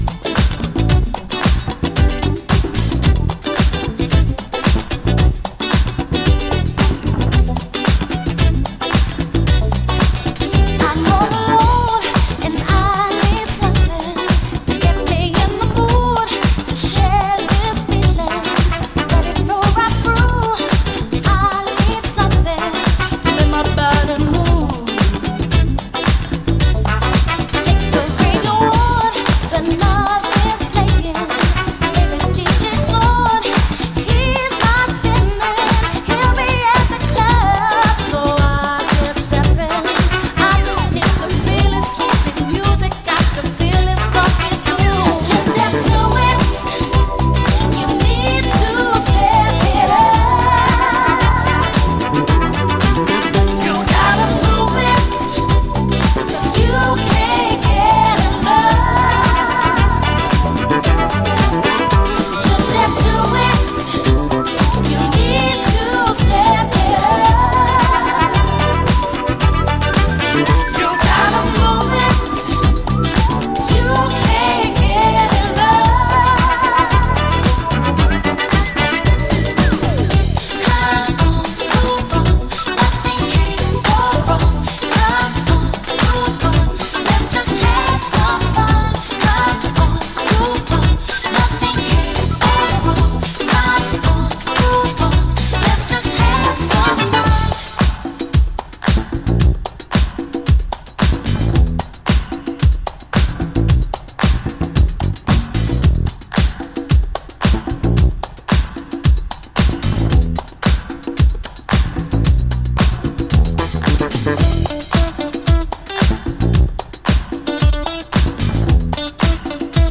Extended Raw Disco Version